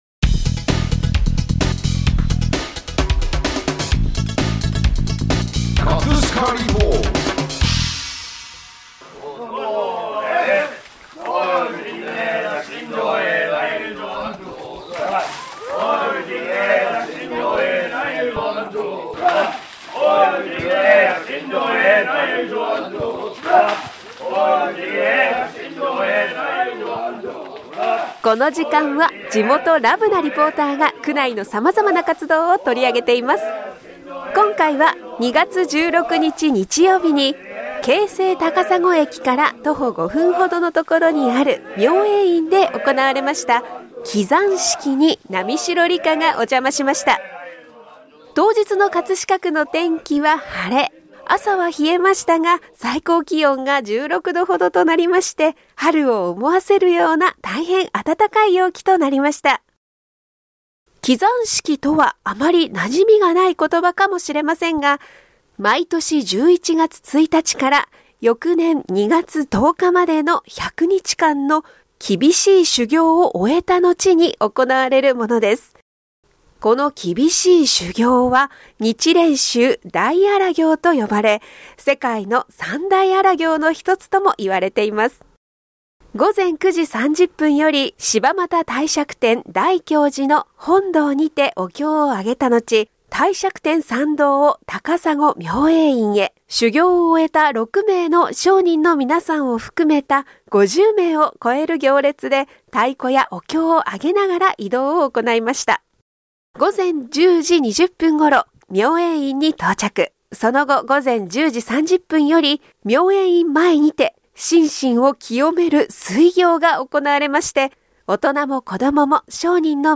会場を訪れていた皆さんの声をどうぞお聞きください！！